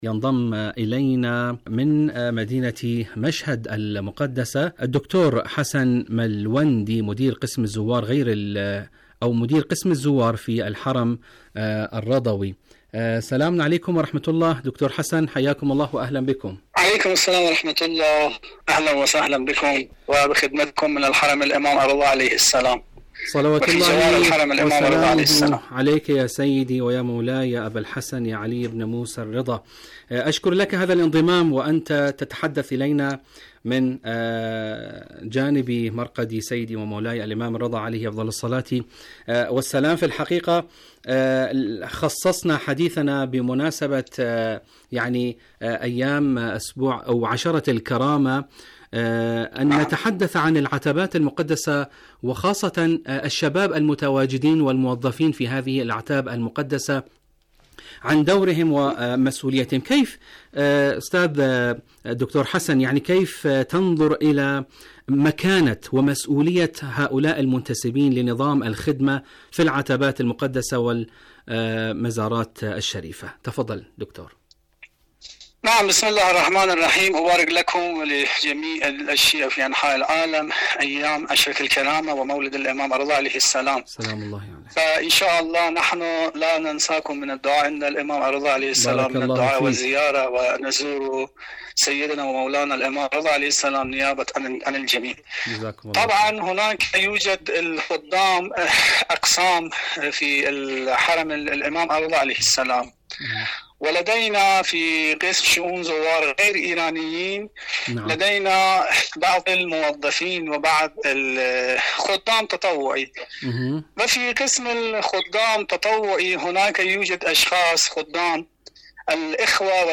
إذاعة طهران- دنيا الشباب: مقابلة إذاعية